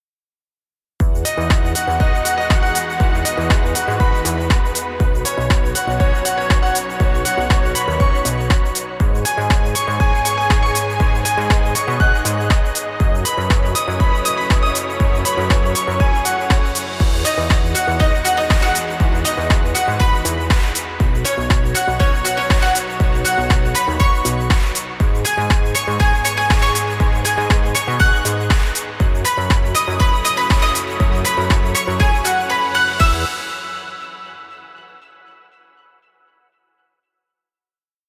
Dance Music for Video.